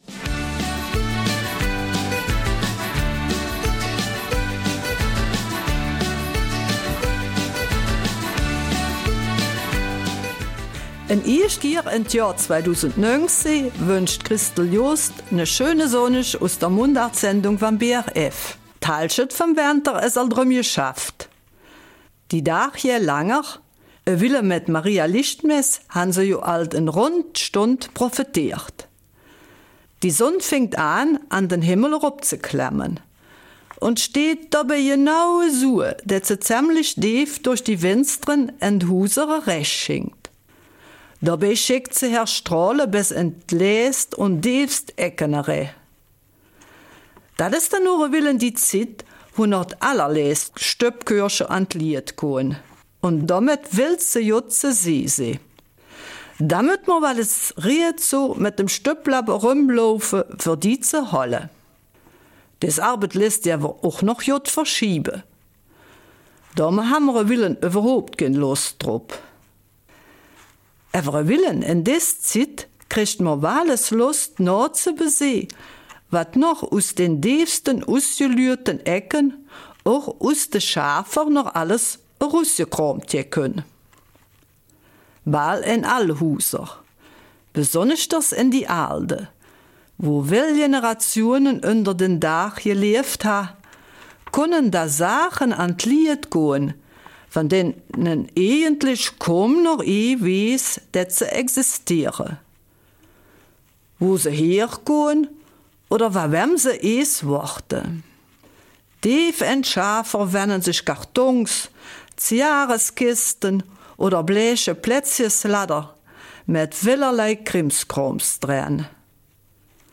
Eifeler Mundart: Zeugnisse der Vergangenheit